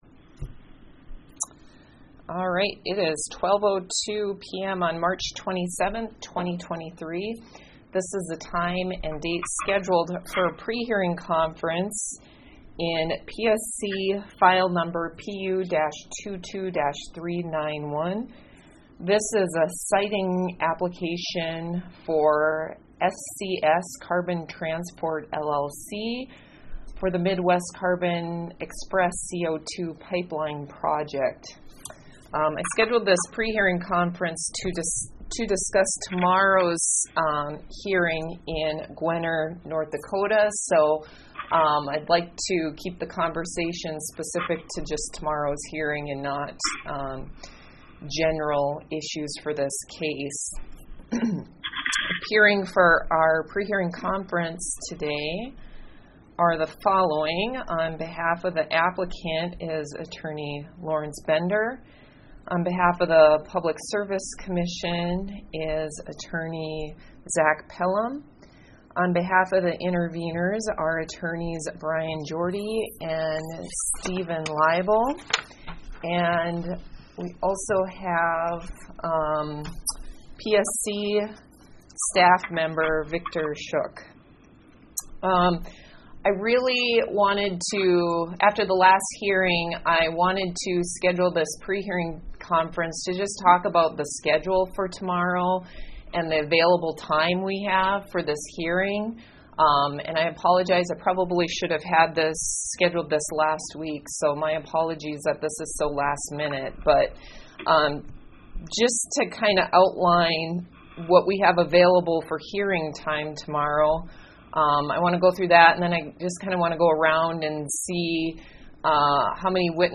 Electronic recording of 27 March prehearing conferece